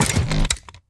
laser-turret-activate-03.ogg